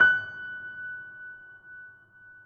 Steinway_Grand